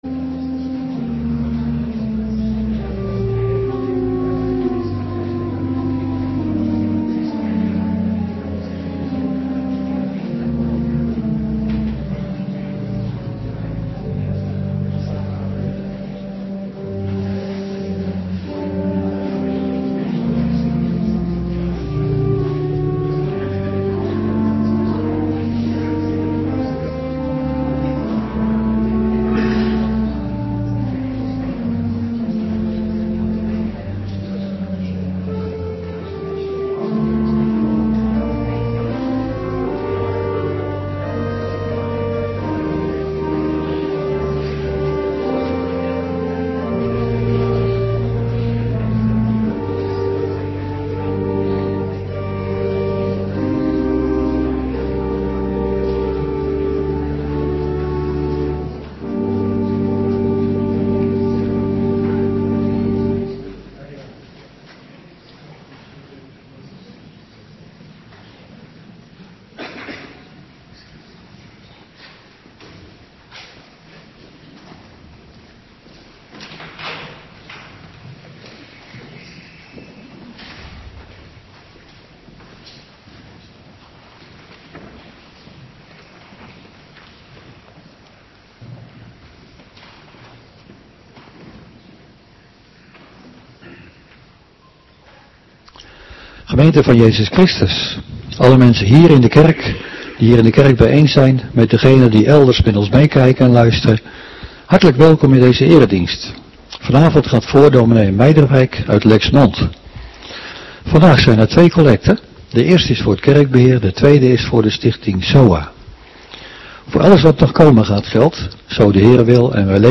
Avonddienst 22 maart 2026